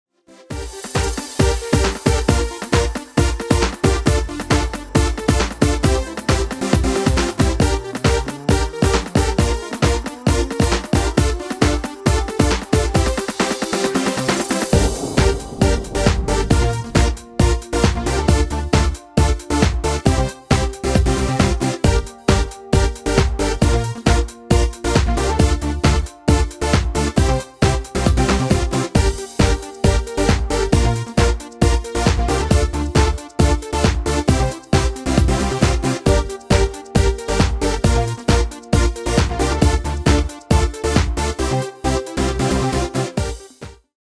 Demo/Koop midifile
Genre: Dance / Techno / HipHop / Jump
Toonsoort: G
- Vocal harmony tracks